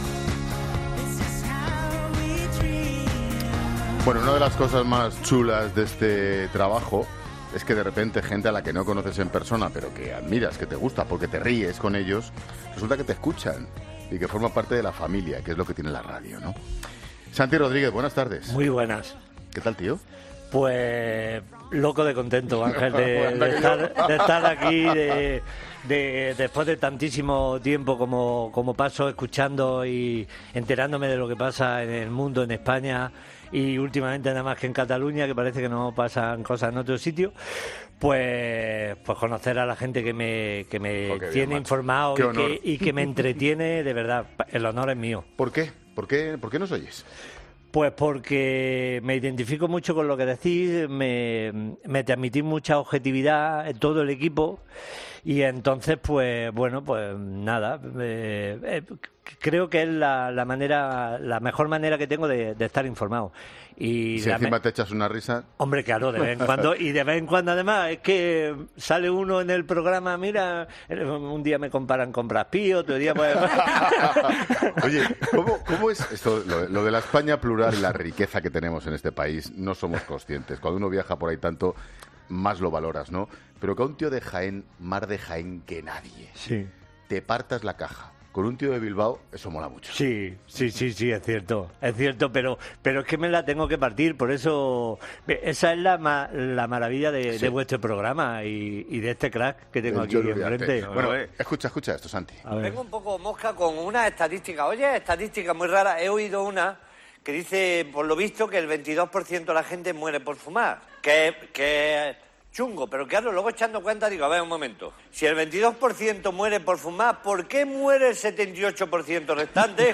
Después de esta unión, si ya es fácil congeniar con el chistoso, la entrevista es un coser y cantar.